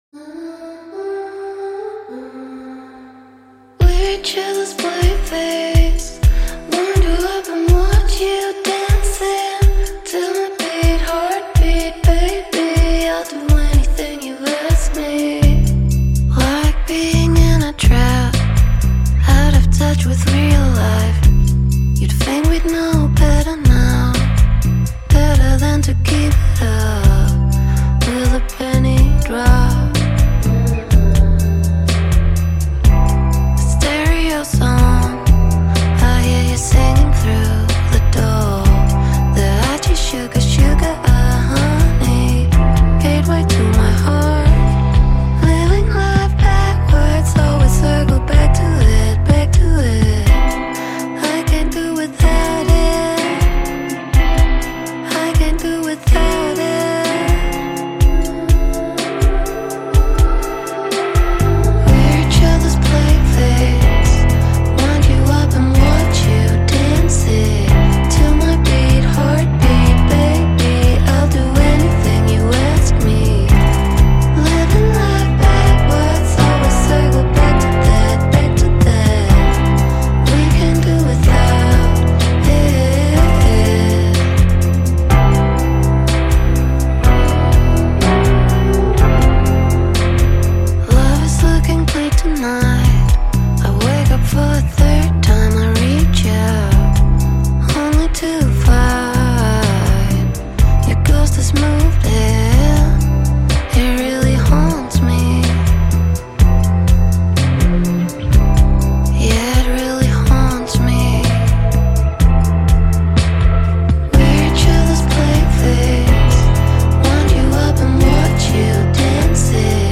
инди рок
Датчане - альтернативщики, дуэт